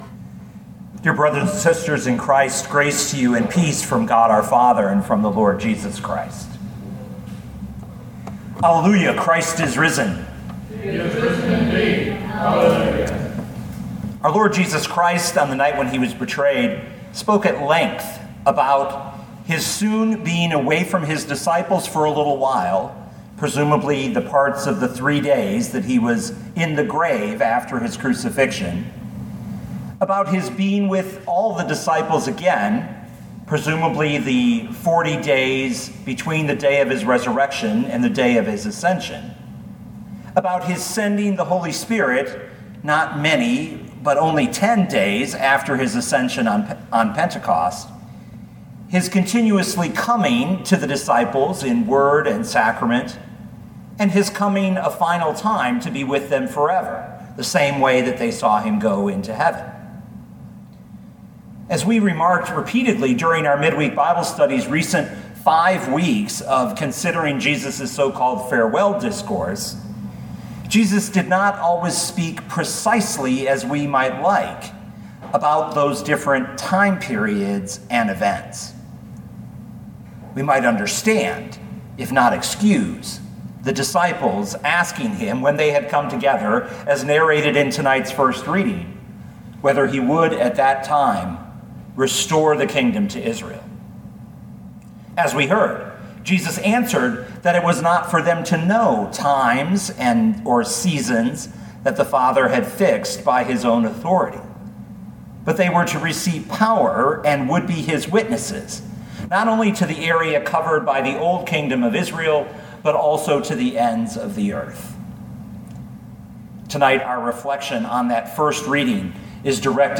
2020 Acts 1:1-11 Listen to the sermon with the player below, or, download the audio.